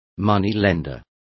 Complete with pronunciation of the translation of moneylender.